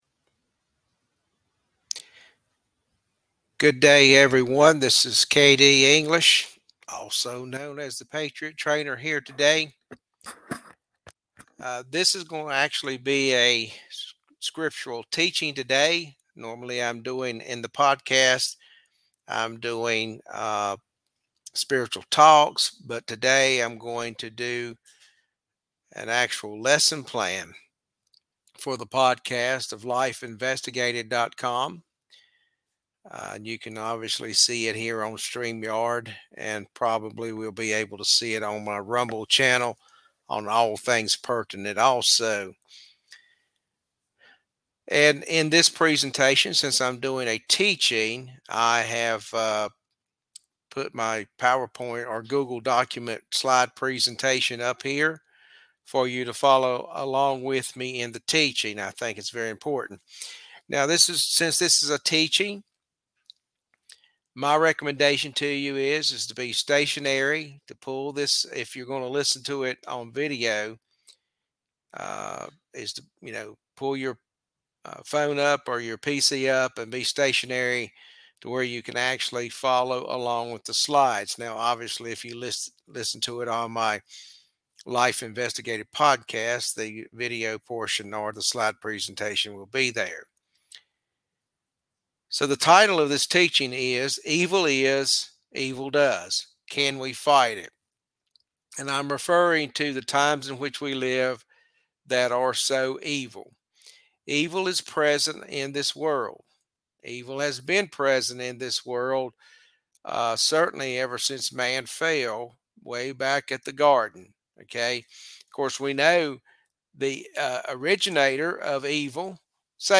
Bible Teaching, Curbing Evil